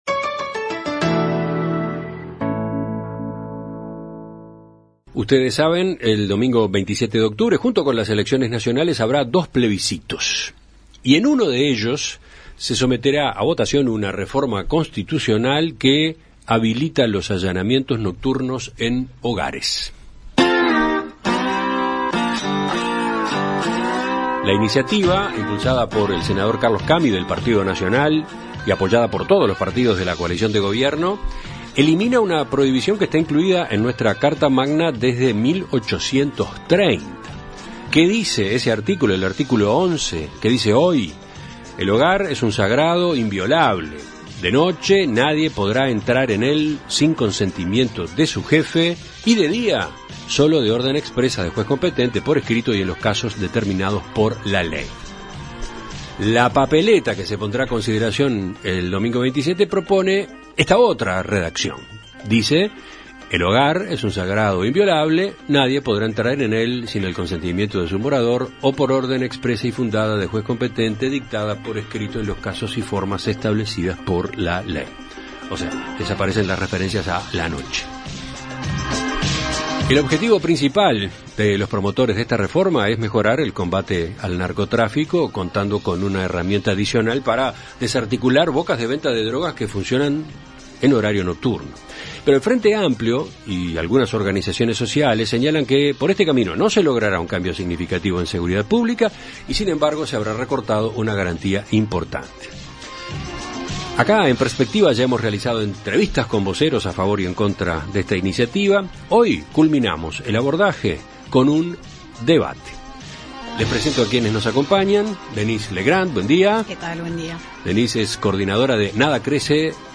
En Perspectiva Zona 1 – Entrevista Central